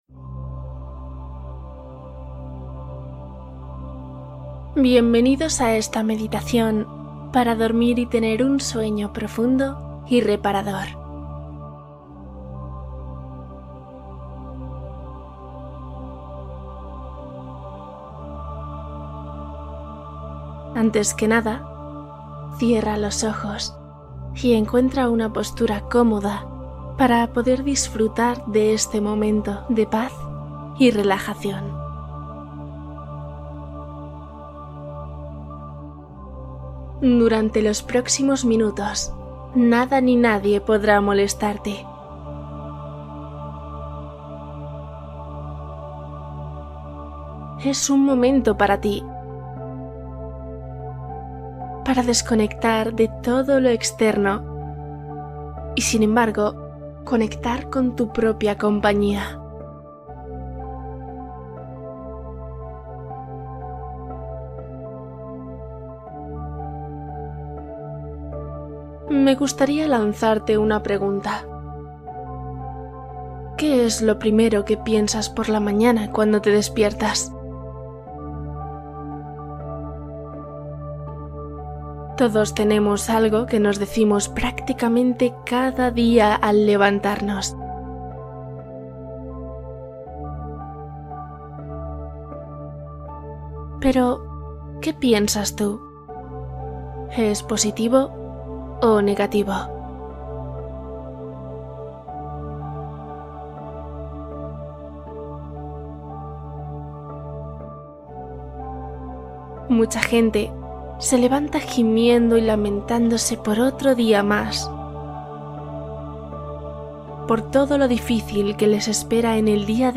Dormir y aliviar estrés | Meditación para sueño reparador